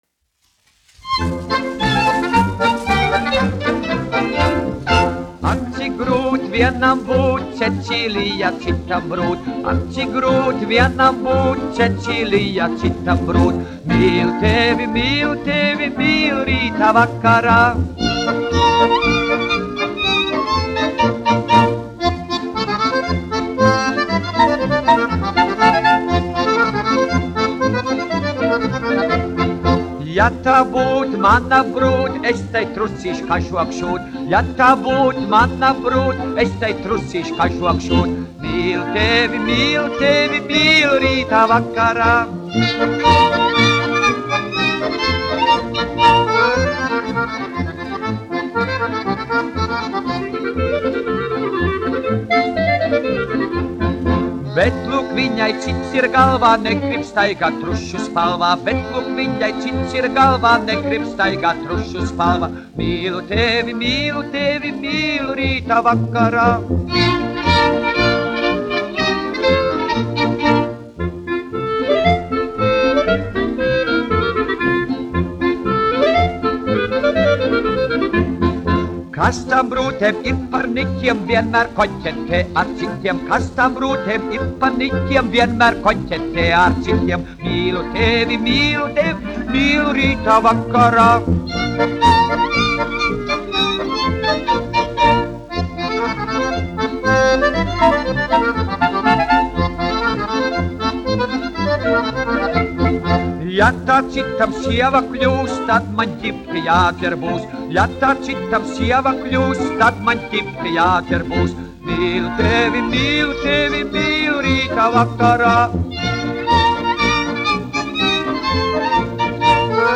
1 skpl. : analogs, 78 apgr/min, mono ; 25 cm
Polkas
Populārā mūzika
Skaņuplate